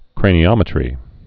(krānē-ŏmĭ-trē)